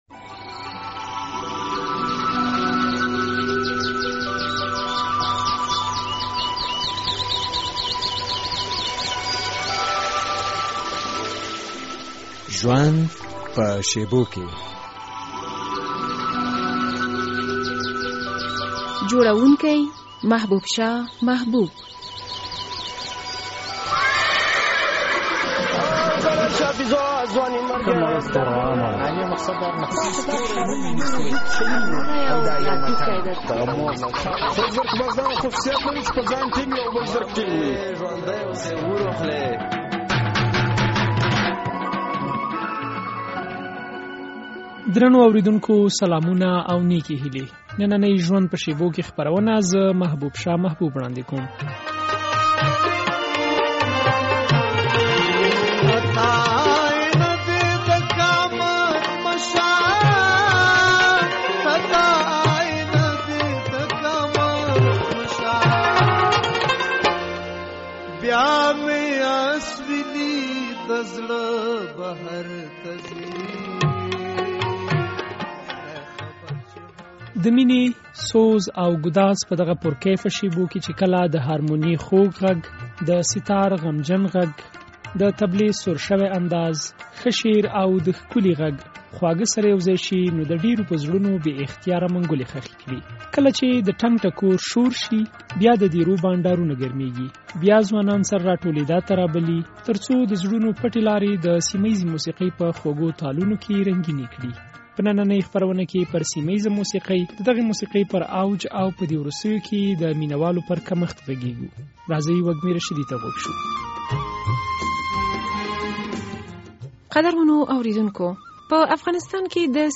د مینې،سوز او ګداز په دغه پُرکیفه شېبو کې چې کله د هارمونیې خوږ غږ، د ستار له غمجن غږ، د طبلې سُر شوی انداز، ښه شعر او د ښکلي غږ خواږه سره یوځای شي نو د ډېرو په زړونو بې اختیاره منګولې خښوي.